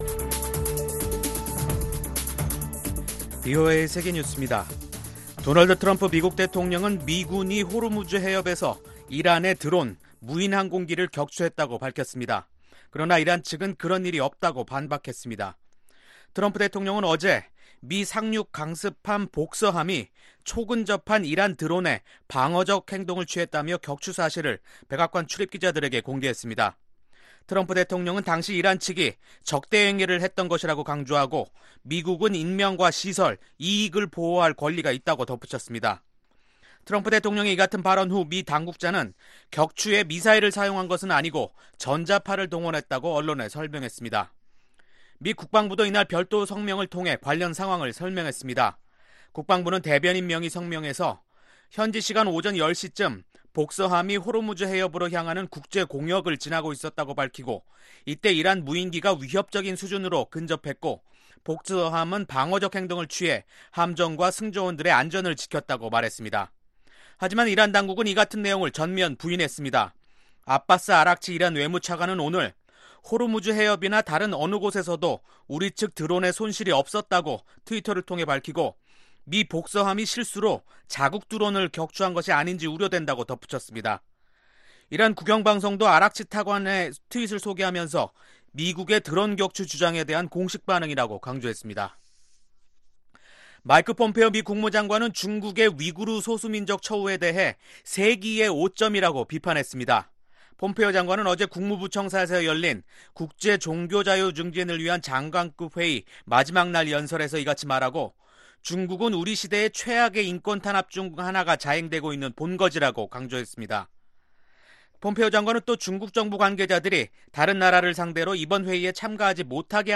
VOA 한국어 간판 뉴스 프로그램 '뉴스 투데이', 2019년 7월 19일 2부 방송입니다. 마이크 펜스 미국 부통령은 북한과의 비핵화 협상 중에도 지속적으로 북한 주민들의 종교자유를 지지할 것이라고 밝혔습니다. 국제적십자 평양사무소는 북한 홍수에 대비해 구호품을 비축하고 당국과 재난 대비책을 집중 논의하고 있습니다.